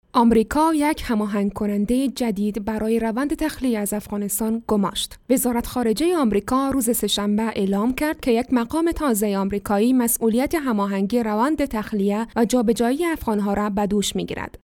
Female
Adult
News-